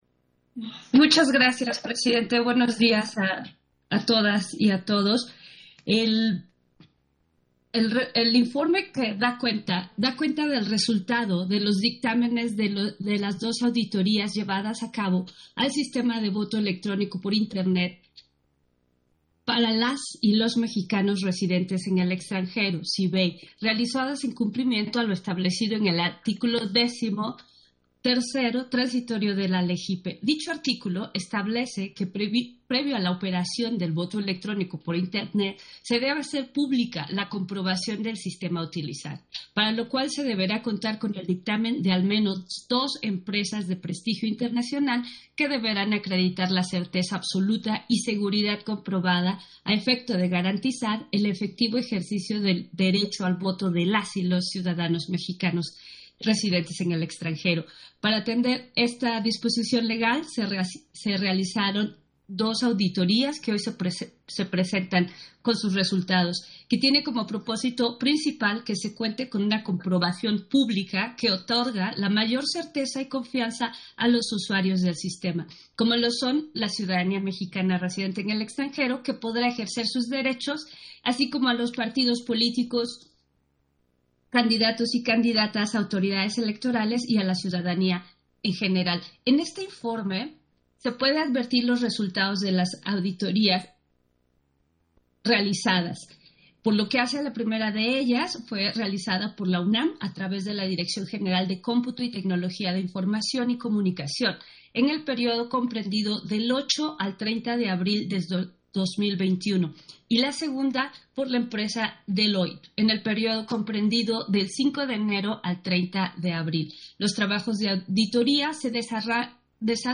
«DR0000_4576.mp3» de TASCAM DR-05.
Intervención de Norma de la Cruz en el punto 9 de la Sesión Ordinaria, relativo al Informe de los dictámenes de auditoría al Sistema de Voto Electrónico en el Extranjero